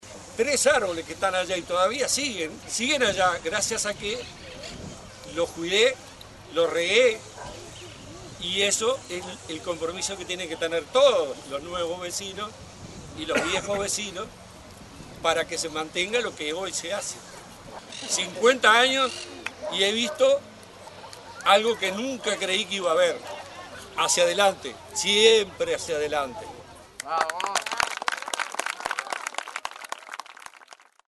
vecino_ciudad_de_la_costa_0.mp3